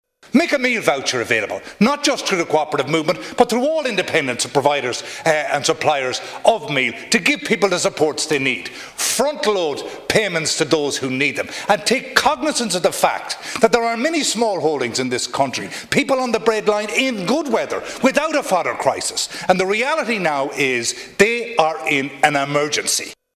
Fianna Fáil’s Mark McSharry says meal vouchers could help relieve farmers’ hardship: